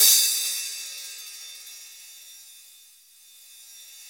Index of /90_sSampleCDs/Club-50 - Foundations Roland/CYM_xReal HiHats/CYM_x13 Hi Hat 2